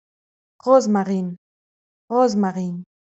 Rosmarin ‘romarin’ (Ladin de Gherdëina)